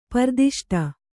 ♪ pardiṣṭa